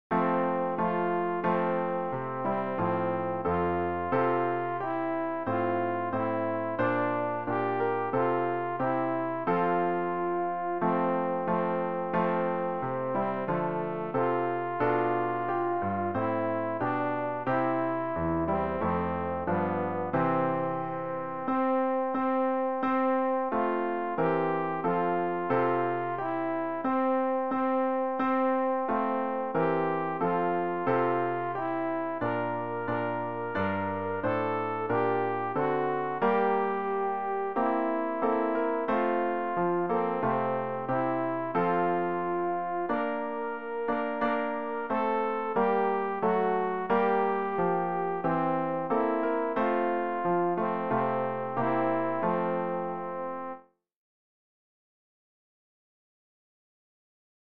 alt-rg-407-unser-heiland-ist-nun-da.mp3